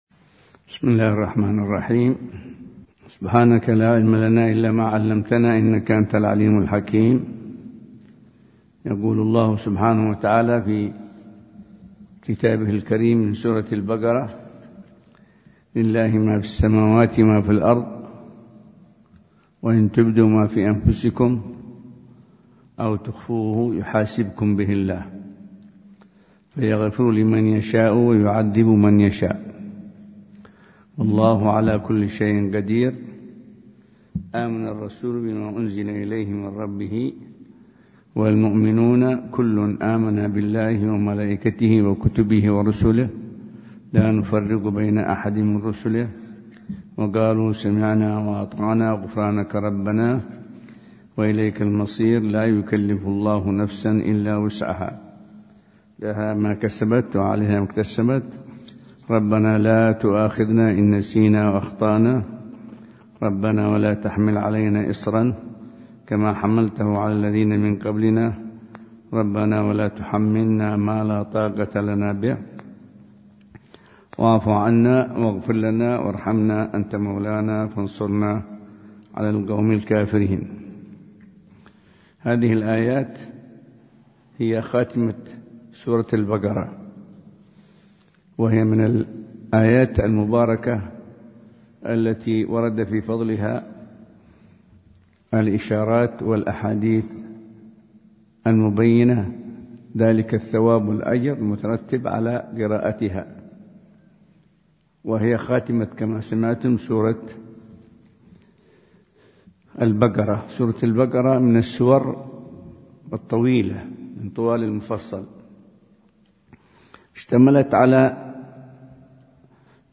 درس التفسير
مسجد الأحمدين بشعب الإمام المهاجر – الحسيسة – حضرموت